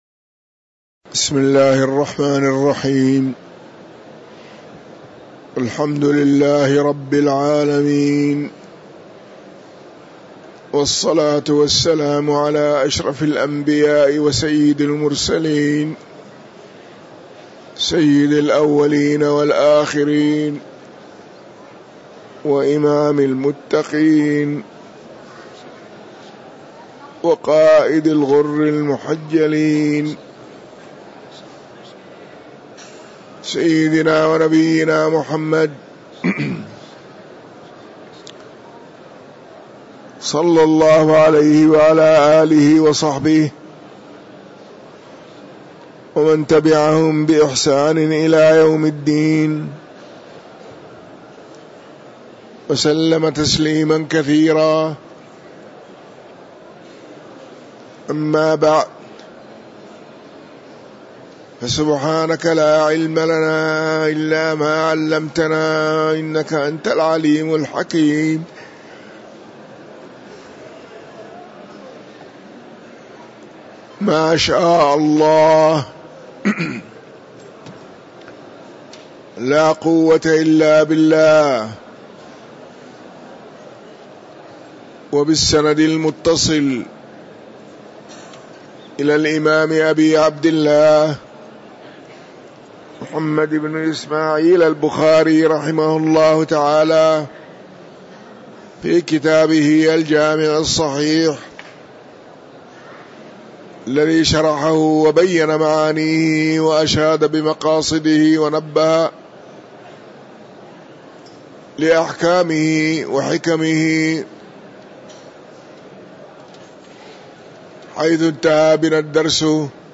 تاريخ النشر ٥ جمادى الأولى ١٤٤٤ هـ المكان: المسجد النبوي الشيخ